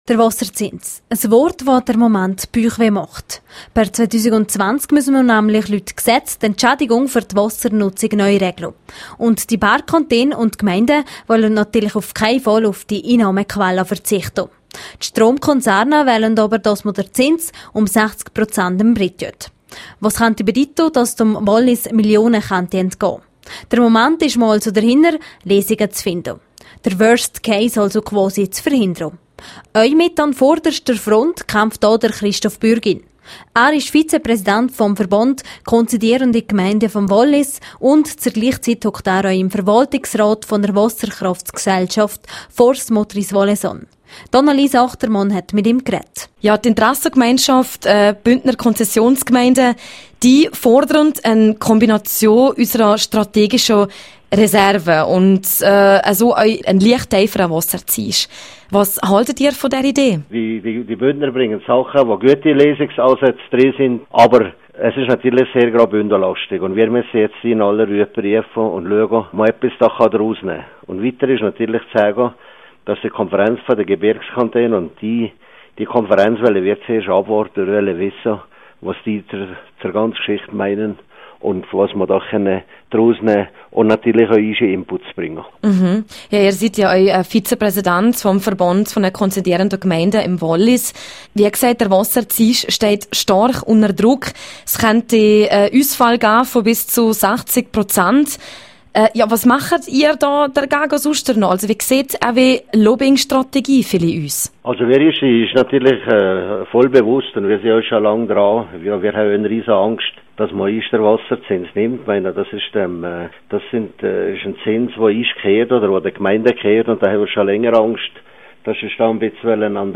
Neues Modell zur Rettung der Wasserzinsen: Interview mit SPO-Grossrat Gilbert Truffer. Quelle: rro